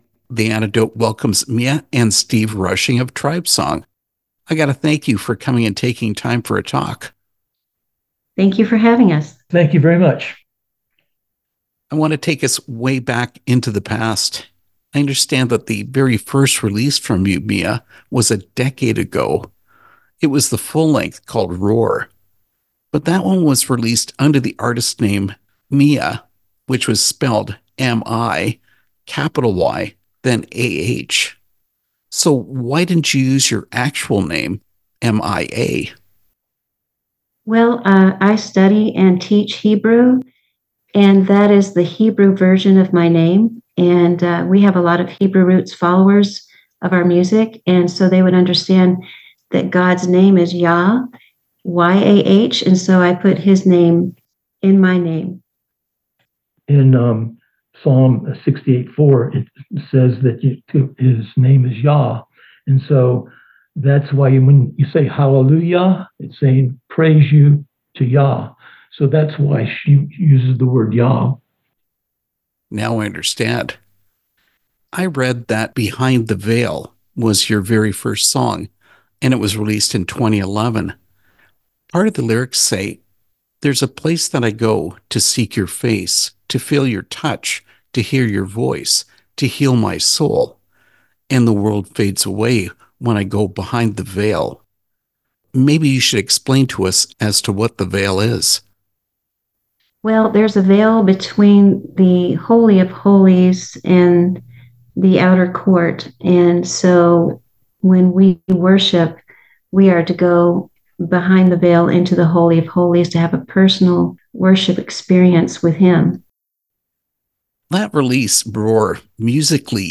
Interview with TribeSong
tribesong-interview.mp3